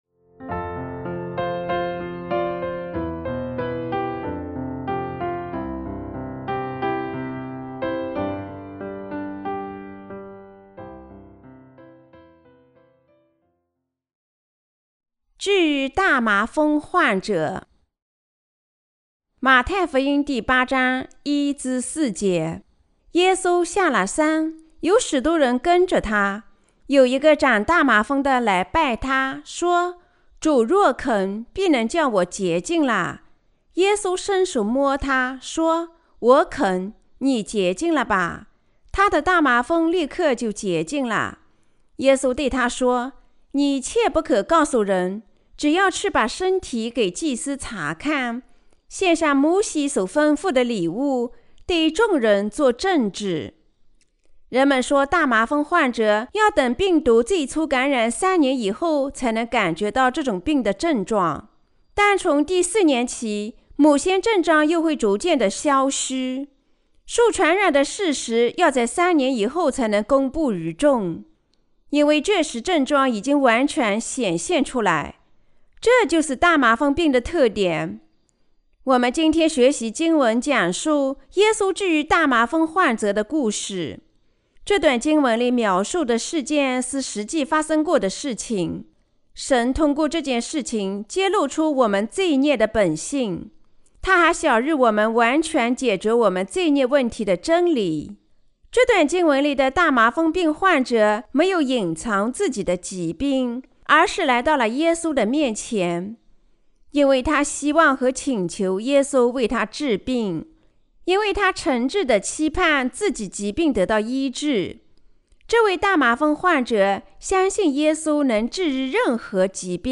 关于马太福音的布道 (Ⅰ) - 基督徒什么时候能和主亲密会谈呢?